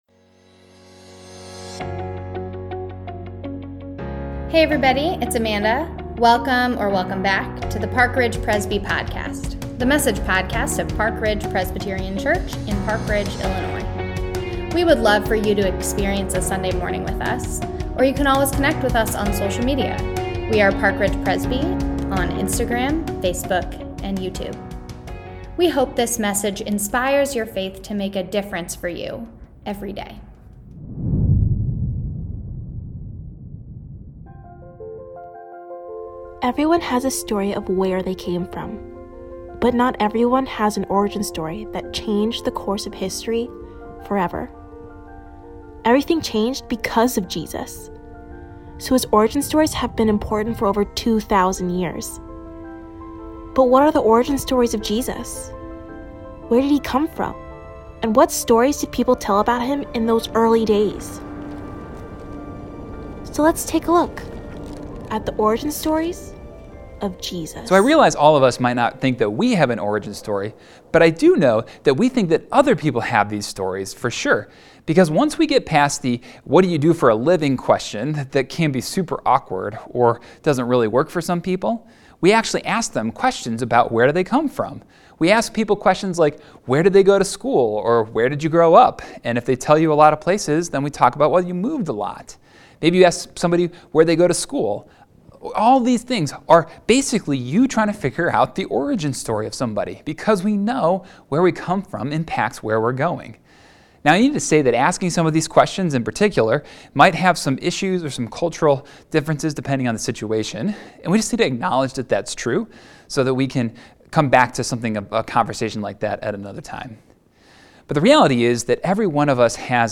The Origin Stories of Jesus – Part 1 | Lent 2022 – Online Worship | April 3, 2022